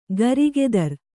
♪ garigedar